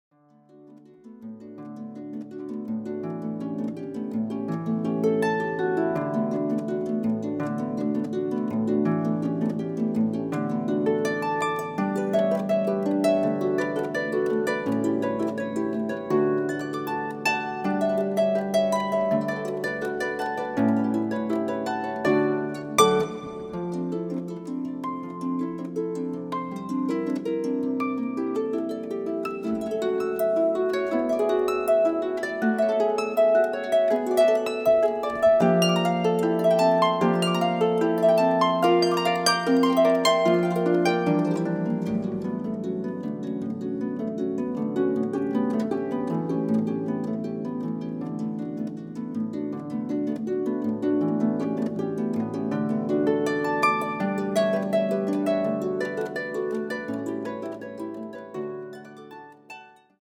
Meisterwerke und einzigartige Arrangements für Harfe